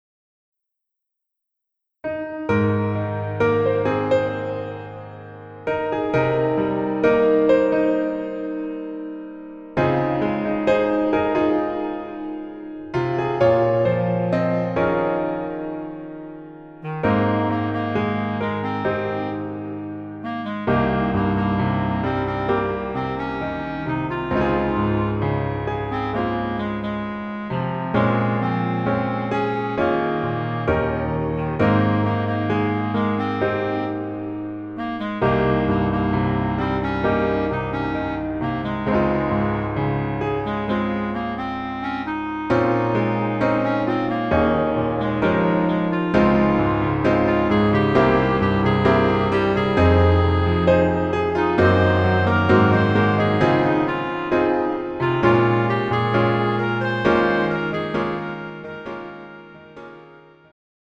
음정 -1키
장르 축가 구분 Pro MR